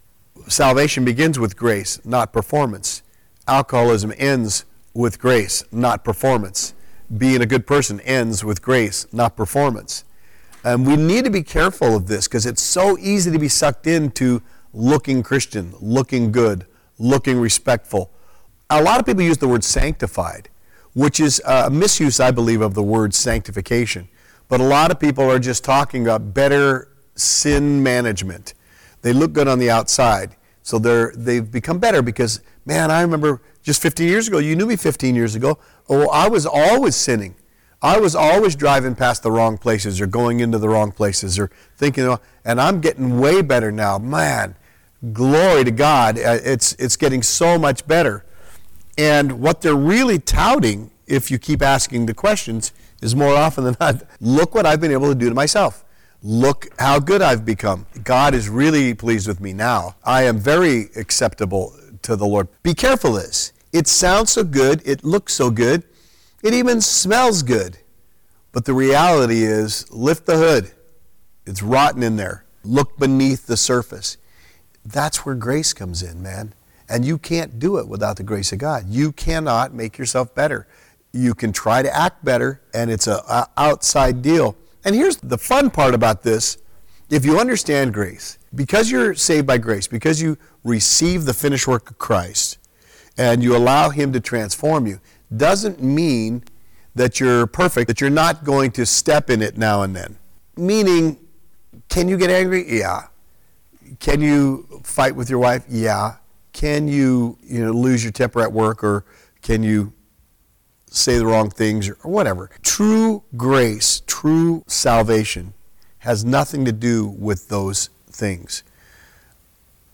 This is an open group discussion around Romans 5:17 and our destiny as those who reign in Christ and what that means. The recording ends abruptly because the mic batteries died.